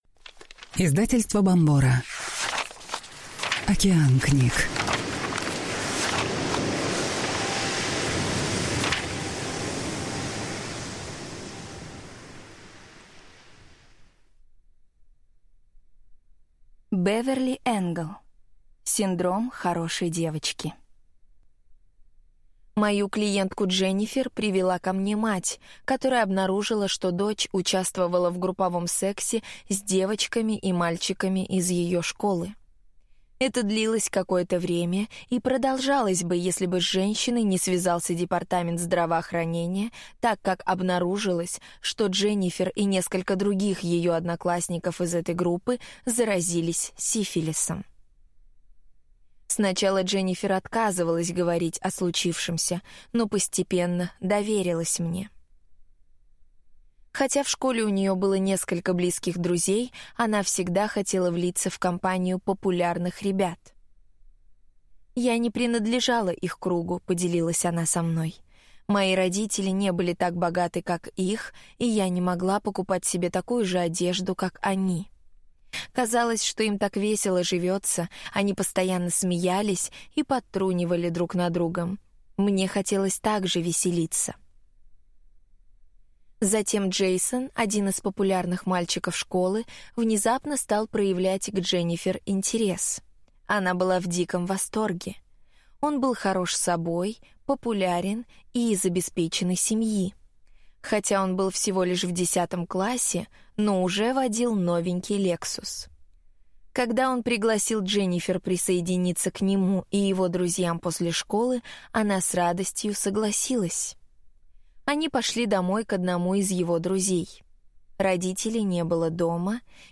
Эта аудиокнига для женщин, которые верят, что получить от окружающих любовь, принятие, заботу и ощущение безопасности возможно только будучи хорошей и удобной.